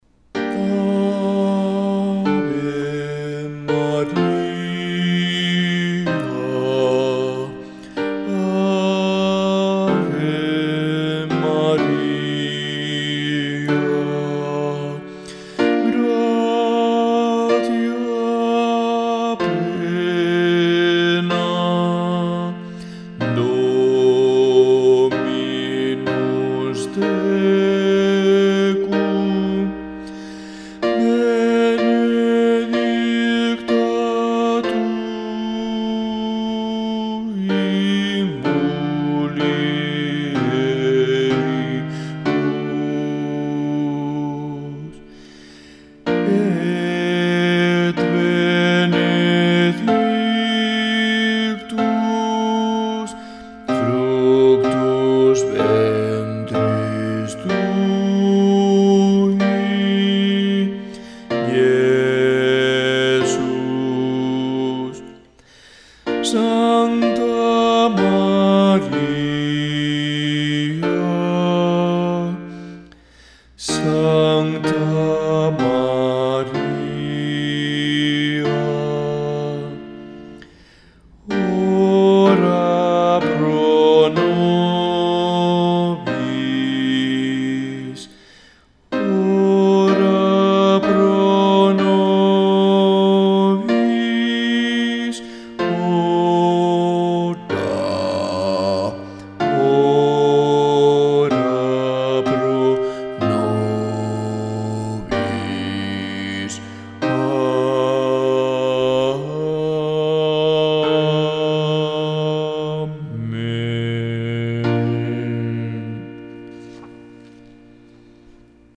Musica SACRA Bajos
Ave-Maria-Scalella-Bajos.mp3